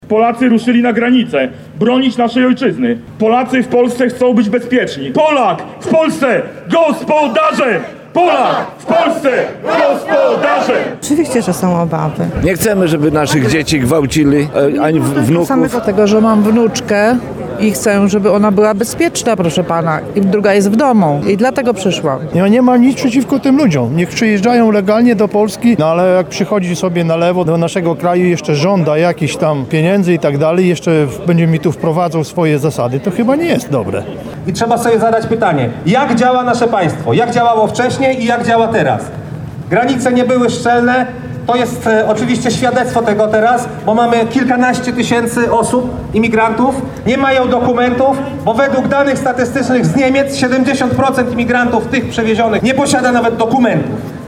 Kilkaset osób w Lublinie bierze udział w manifestacji „Stop imigracji” przed lubelskim Ratuszem.
– Są obawy. Mam wnuczkę i chcę, żeby była bezpieczna – mówi jedna z protestujących.